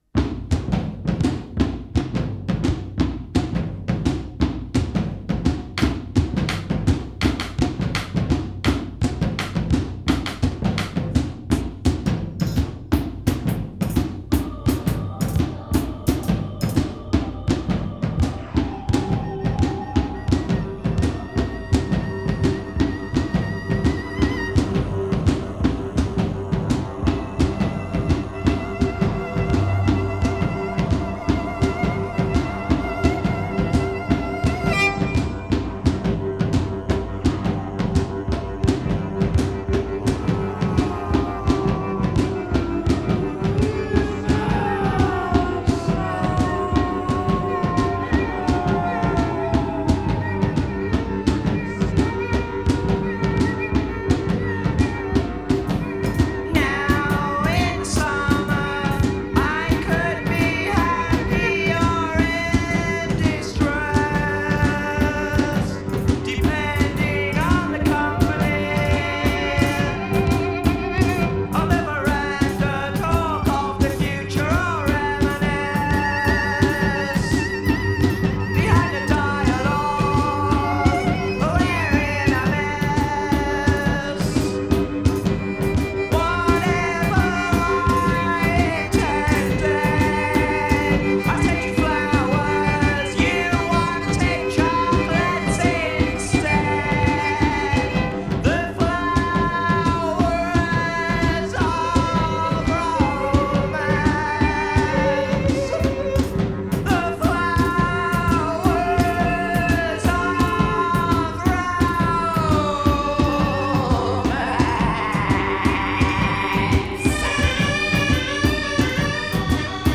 percussion-heavy